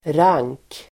Uttal: [rang:k]